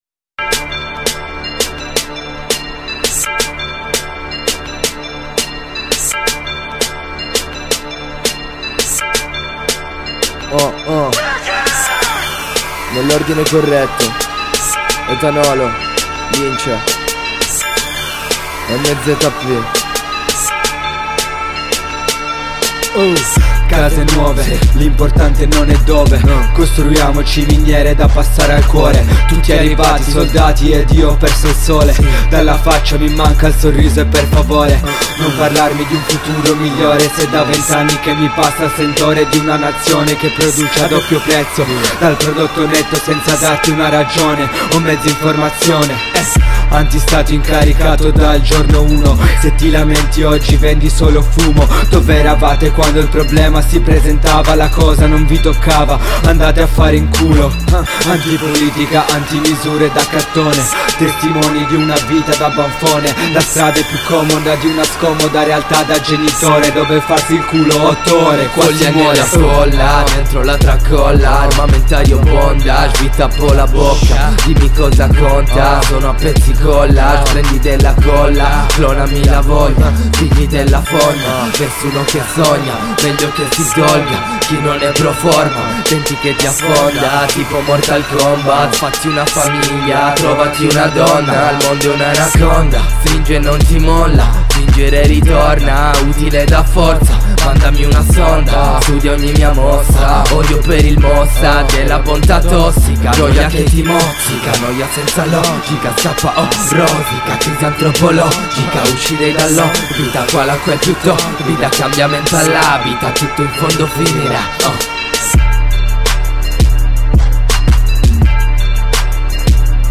collettivo Hip-Hop